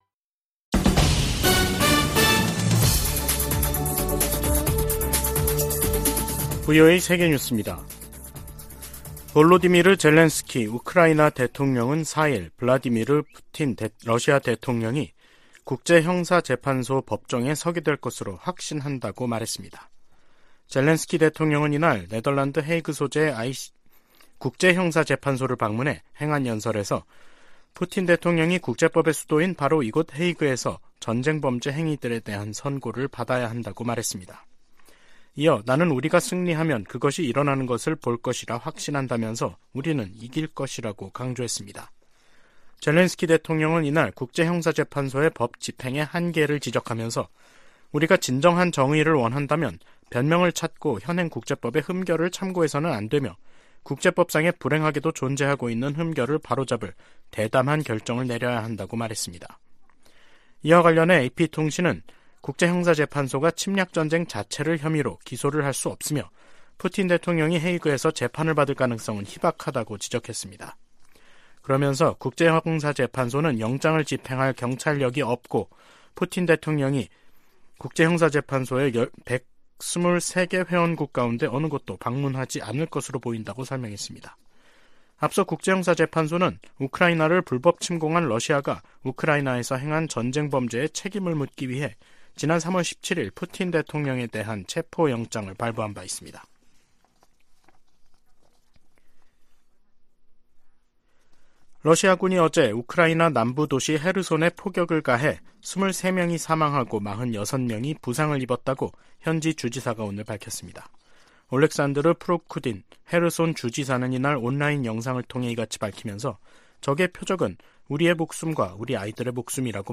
VOA 한국어 간판 뉴스 프로그램 '뉴스 투데이', 2023년 5월 4일 3부 방송입니다. 미 국무부가 기시다 후미오 일본 총리의 한국 방문 계획을 환영했습니다.